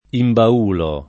imbaulare v.; imbaulo [ imba 2 lo ]